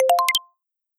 celebration.wav